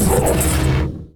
attack3.ogg